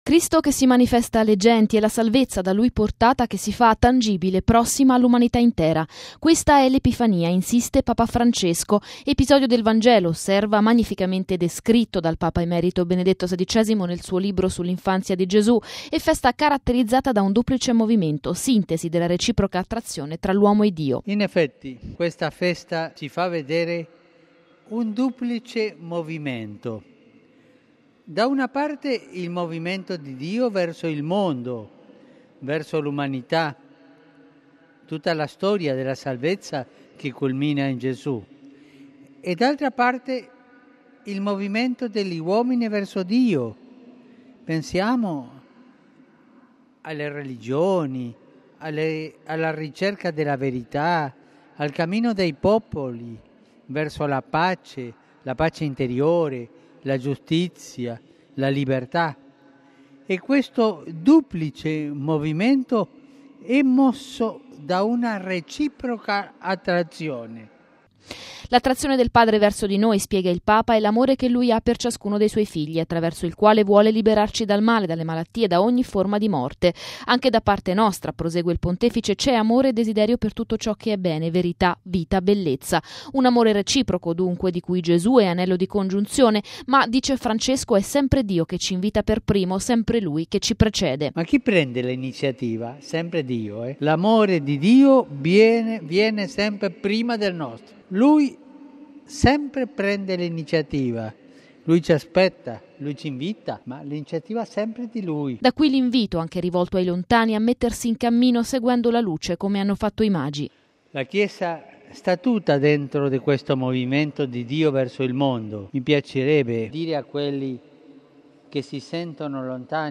◊   All’Angelus in Piazza San Pietro, davanti a circa 70-80 mila persone, Papa Francesco è tornato sull’Epifania, definendola una solennità che mette in risalto l’apertura universale della salvezza, portata da Gesù e che mostra un duplice movimento di Dio verso il mondo e dell’umanità intera verso Dio. Al termine, i saluti ai fedeli delle Chiese Orientali, che domani celebreranno il Santo Natale, e il ricordo dell’odierna Giornata Missionaria dei Bambini.